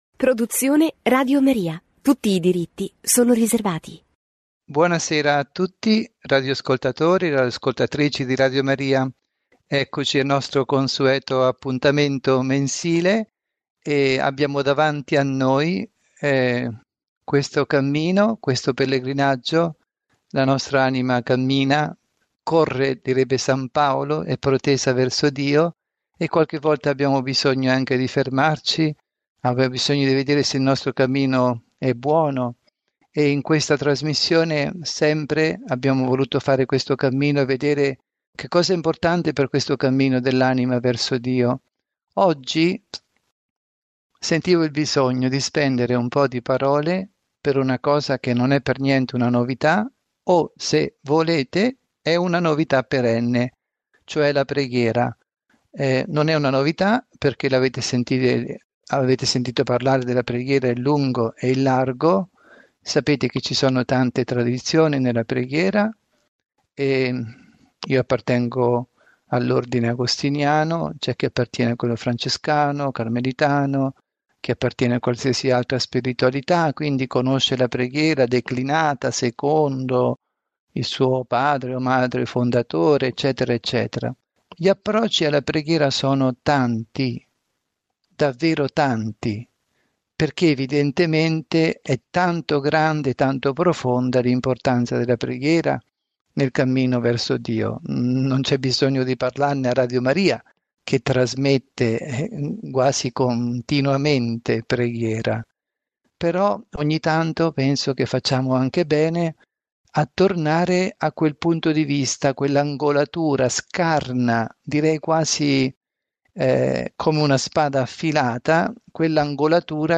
Catechesi
trasmessa in diretta su Radio Maria